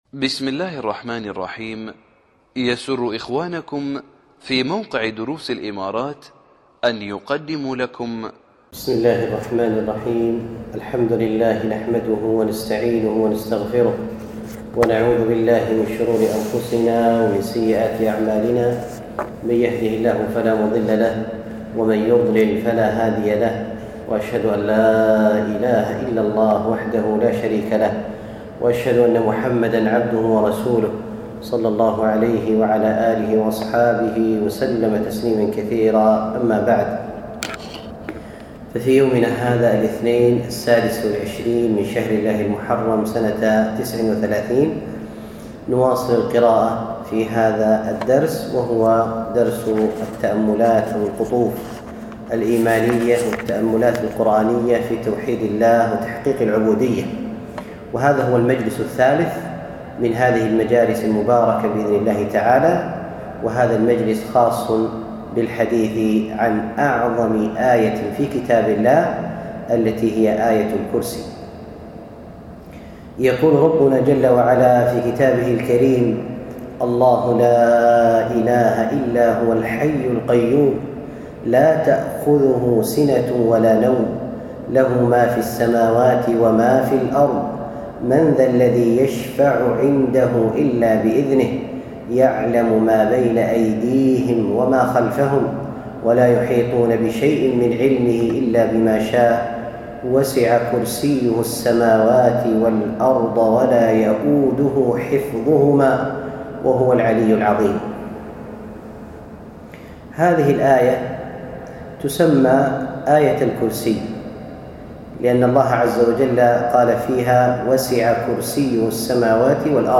الدرس الأول